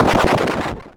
explosion-b.ogg